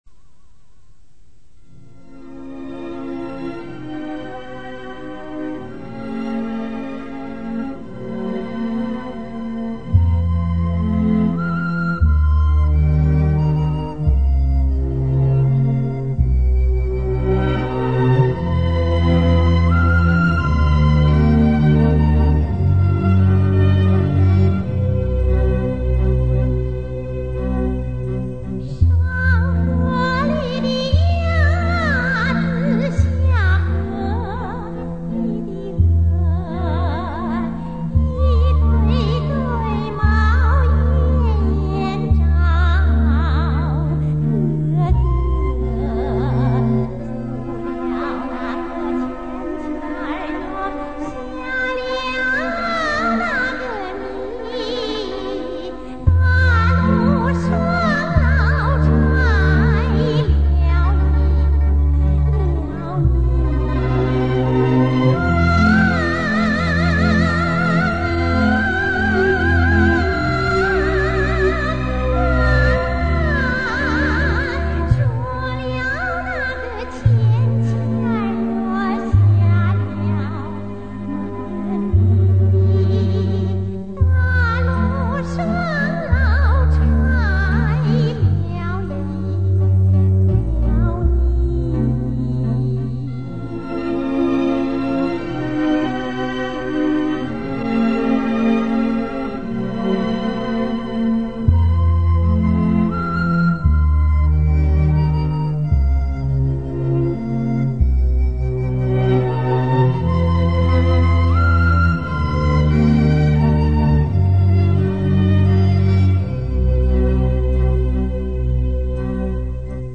本专辑制作采用杜比音效制作
历史录音 不要与发烧碟相提并论